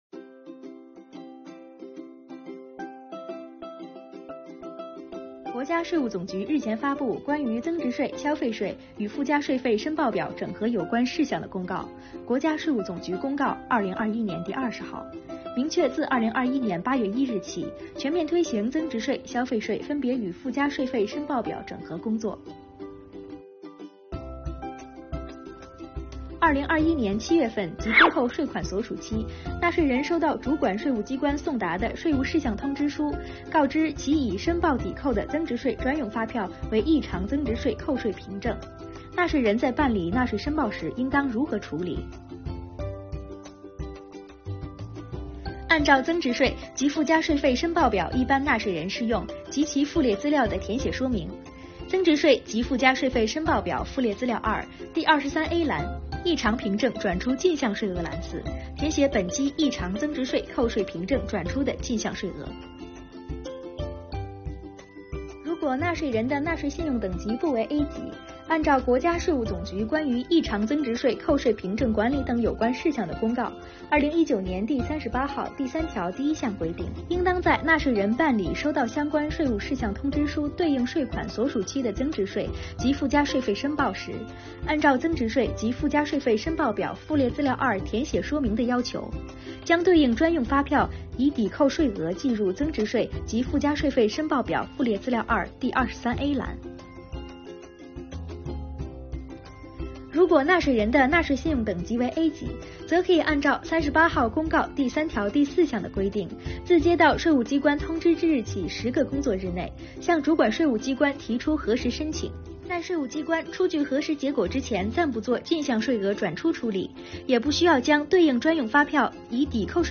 动画制作、配音